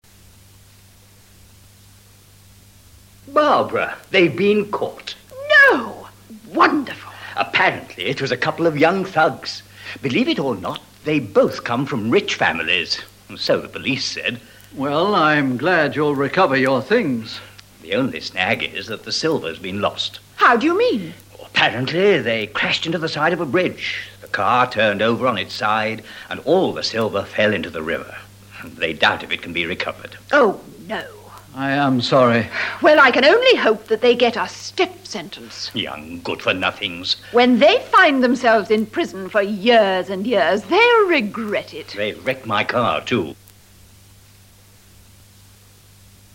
Harry has just heard from the police about their robbery. This dialogue expresses revenge.
dialog02a.mp3